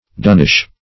Dunnish \Dun"nish\ (d[u^]n"n[i^]sh)